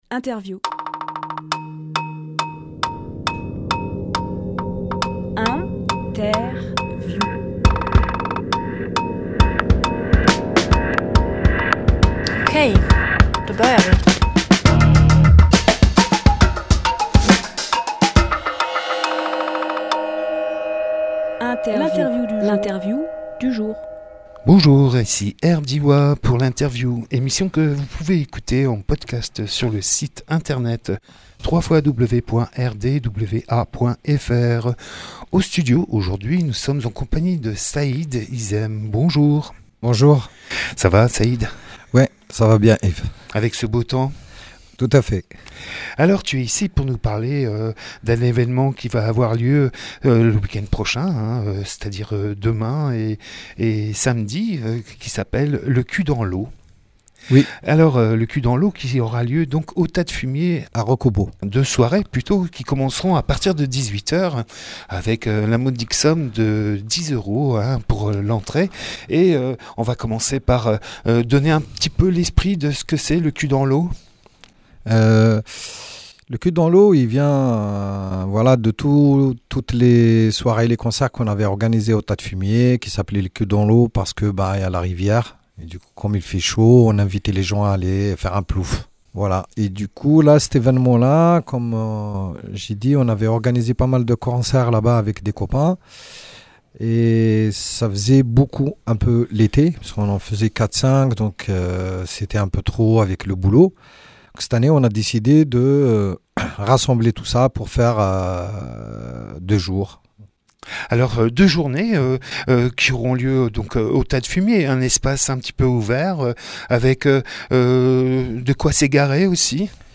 Emission - Interview Le Q dans l’O au Tas de fumier Publié le 29 août 2018 Partager sur…
Lieu : Studio RDWA